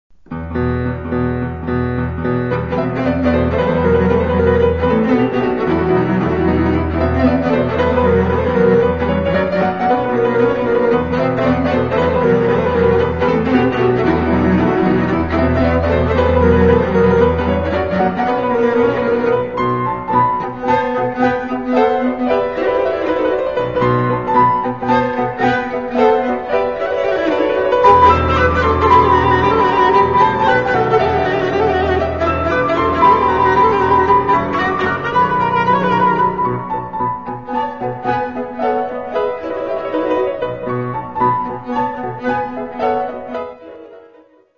violoncelle
piano
Oeuvres pour violoncelle et piano